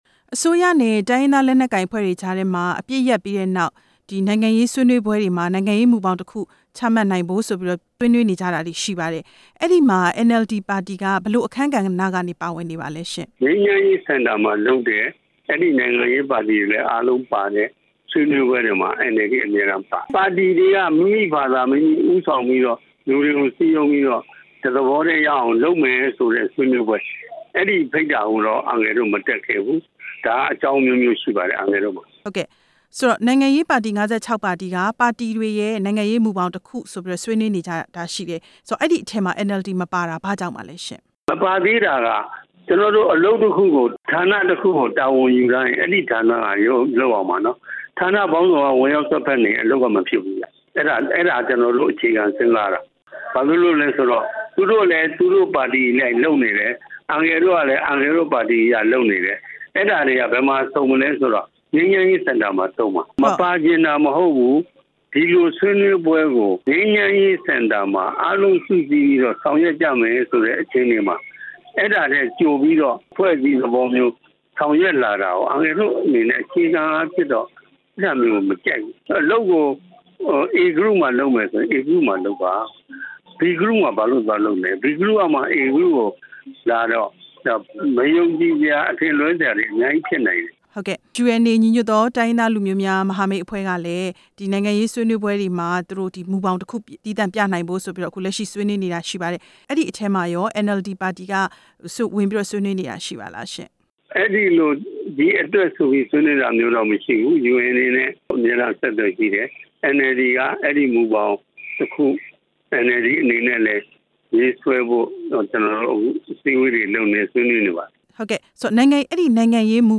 နိုင်ငံရေးမူဘောင်ရေးဆွဲရေး NLD နဲ့ မေးမြန်းချက်